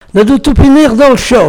Enquête Arexcpo en Vendée
locutions vernaculaires